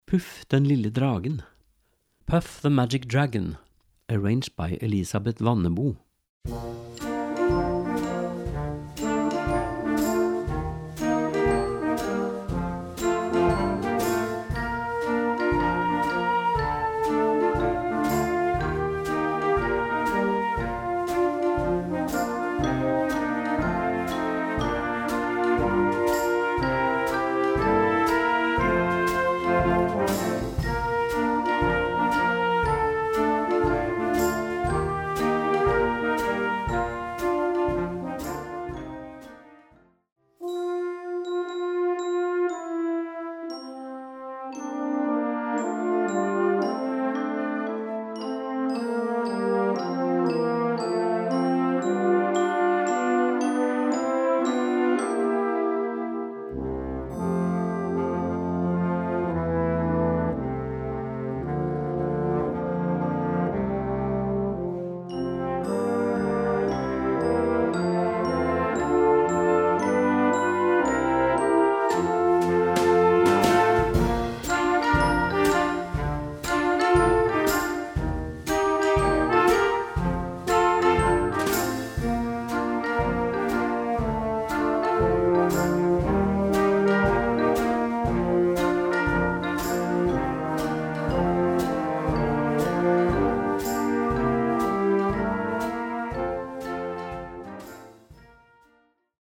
für Jugendblasorchester
Besetzung: Blasorchester